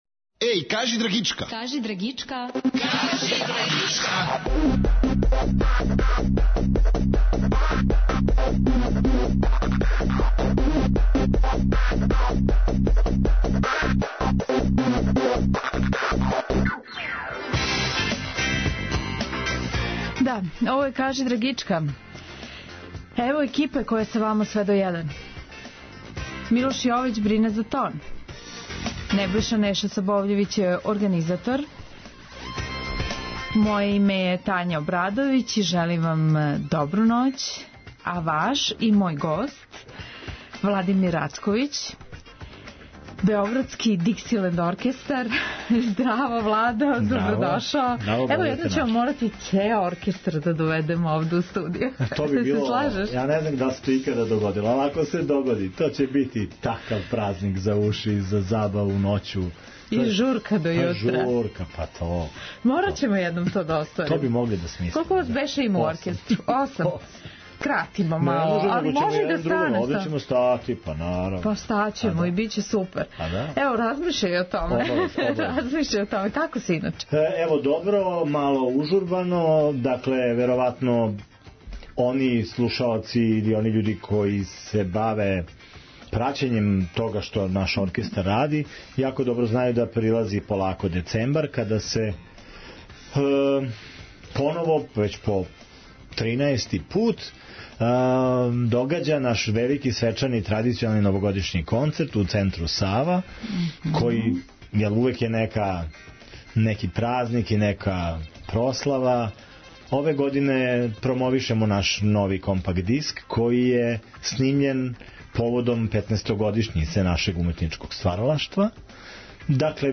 Имаћемо прилике да преслушамо композиције са новог албума, које звучно верно илуструју све историјски кључне параметре који су довели до џеза каквог га данас сви знамо.